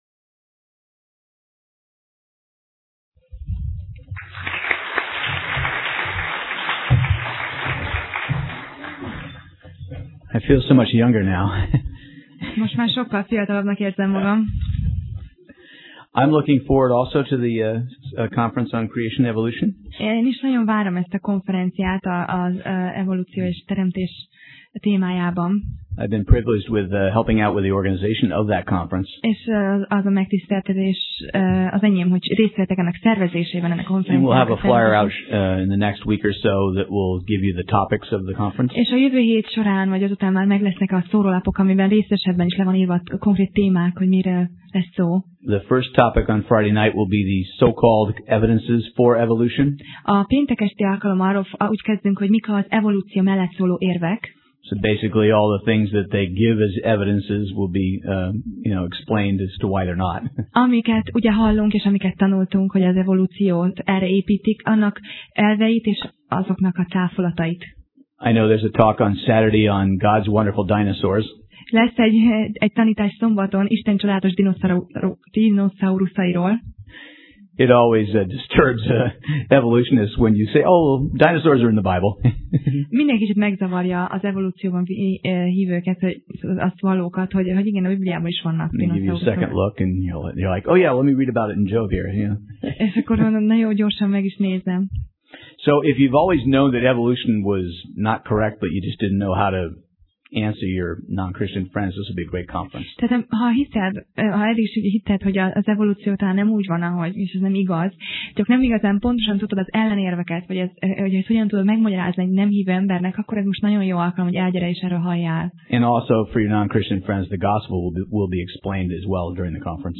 Sorozat: Tematikus tanítás Passage: Máté (Matthew) 18:21-35 Alkalom: Vasárnap Reggel